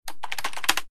keyboard5.ogg